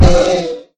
sounds / mob / horse / zombie / hit1.mp3